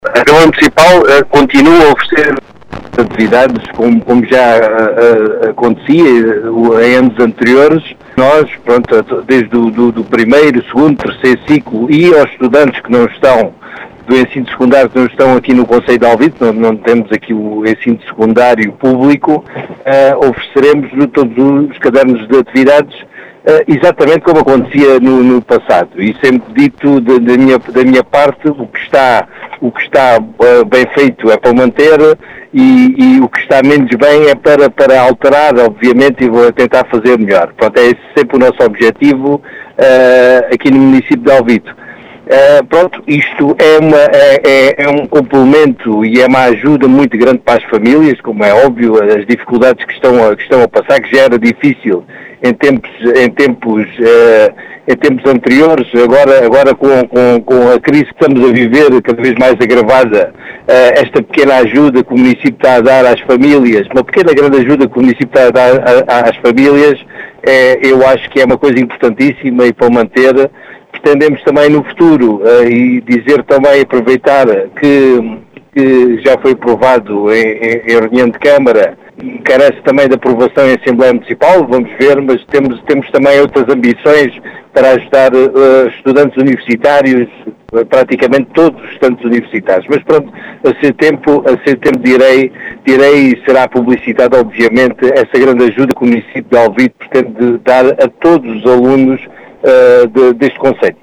As explicações são de José Efigénio, presidente da Câmara de Alvito, que realçou a importância desta “pequena grande ajuda” para as famílias do concelho,  que num futuro próximo será extensível a todos os alunos do concelho.